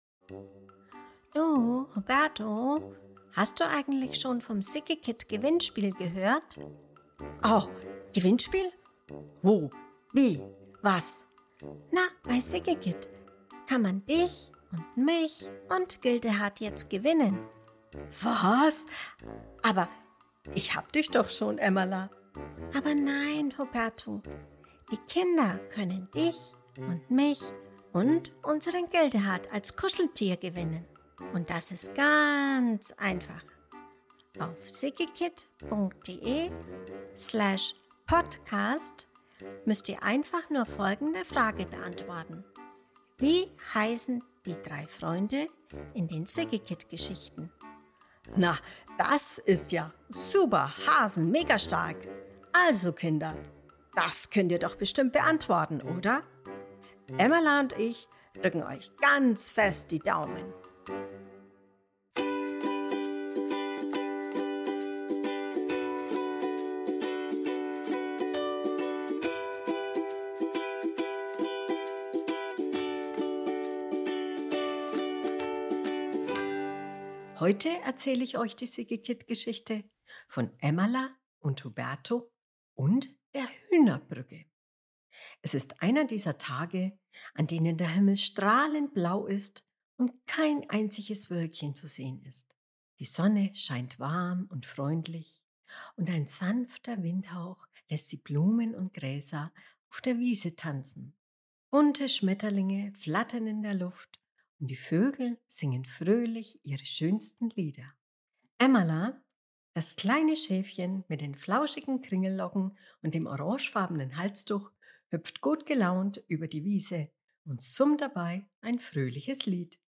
Juni 2025 Kinderblog Vorlesegeschichten, Emmala & Huberto Ein sonniger Tag auf der Wiese: Emmala und Huberto treffen Trude, das neugierige Huhn, das zurück zum Bauernhof möchte.